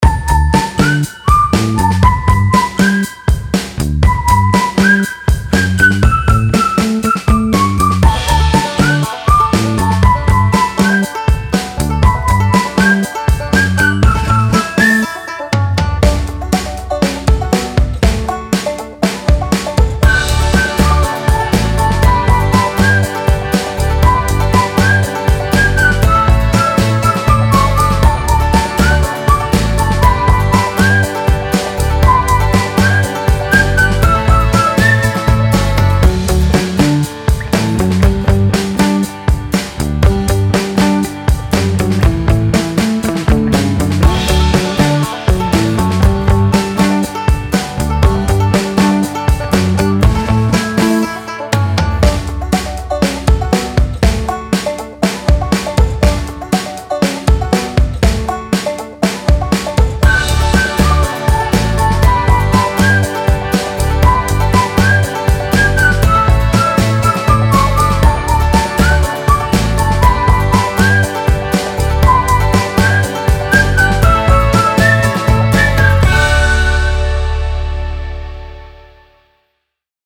INDIE FOLK
HAPPY INDIE FOLK
Positive / Easy / Acoustic / Chill